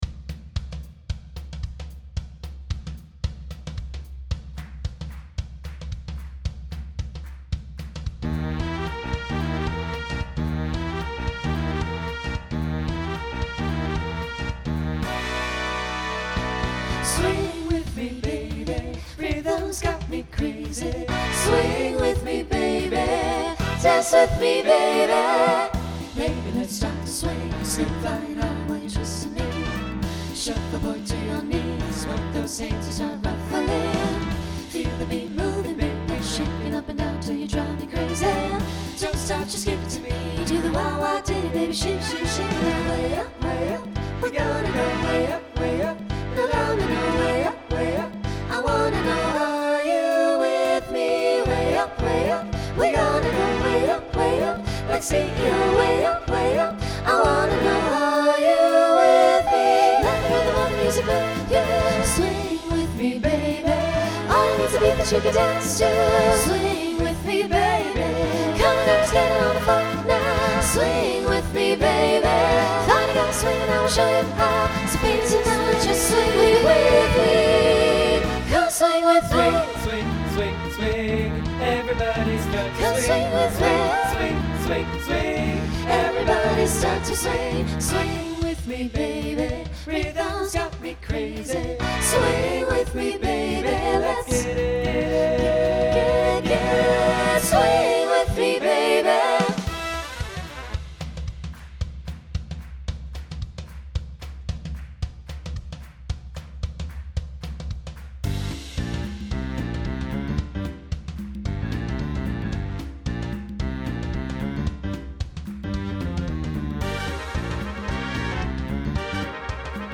Swing/Jazz
Closer Voicing SATB